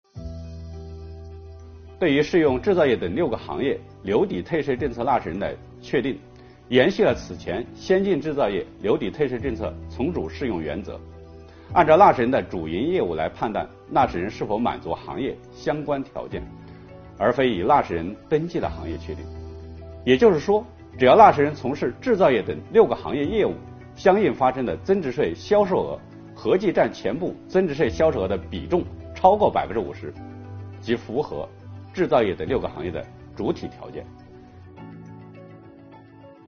国家税务总局货物和劳务税司副司长刘运毛担任主讲人，详细解读了有关2022年大规模留抵退税政策的重点内容以及纳税人关心的热点问题。